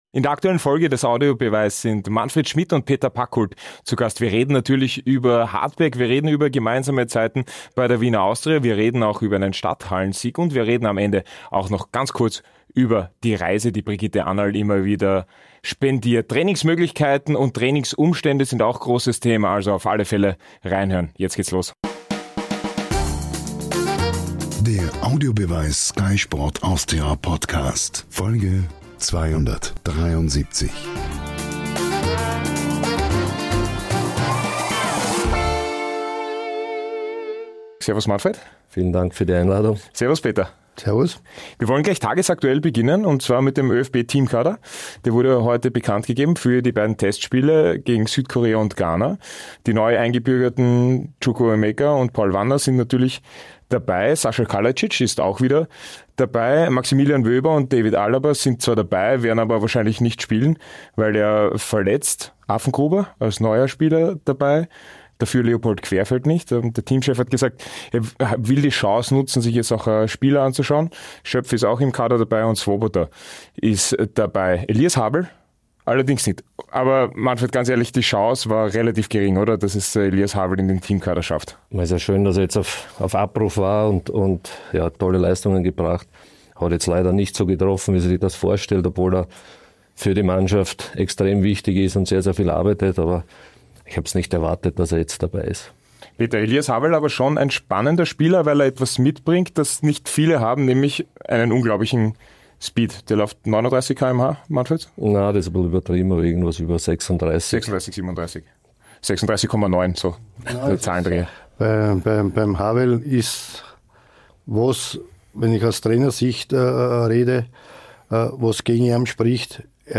den beiden Sky-Experten Peter Pacult & Marc Janko